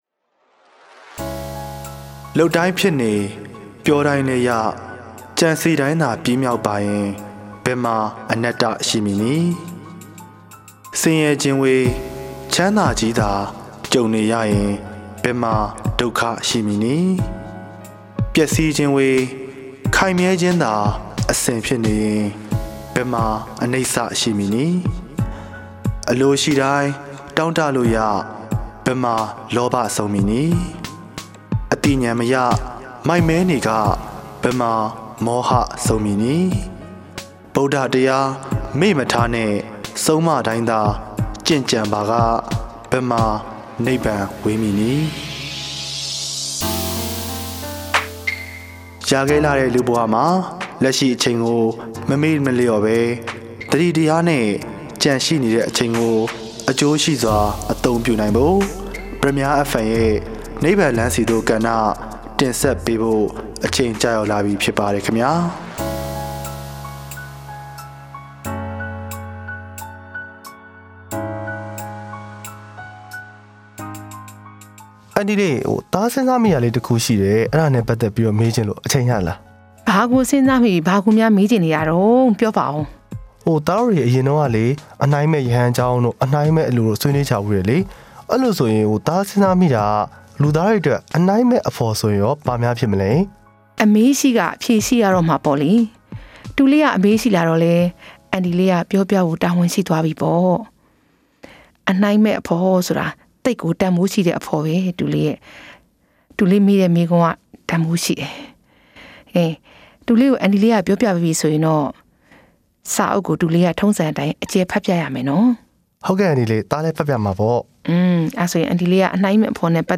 15:30 "အနှိုင်းမဲ့ အဖော်" ဗုဒ္ဓ၏ ဓမ္မနှင့် ပတ်သက်သည့် စာကောင်းပေမွန်များထဲမှ နိဗ္ဗာန်ရောက်ကြောင်း စာပေများကို ရွေးချယ်ပြီး တူက အဒေါ်ကို စာဖတ်ပြသည့် ပုံစံ ဖြင့်တင်ဆက်ထားသည့် နိဗ္ဗာန်လမ်း ဆီသို့ …. nbl 130-padamyarfm-fm-myanmarfm.mp3 Date/Time